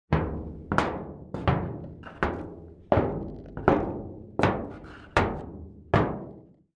Echoing Footsteps In Metal Hallway Sound Effect
Description: Echoing footsteps in metal hallway sound effect. Realistic audio of footsteps resonating through a metallic corridor, creating an industrial and suspenseful atmosphere.
Echoing-footsteps-in-metal-hallway-sound-effect.mp3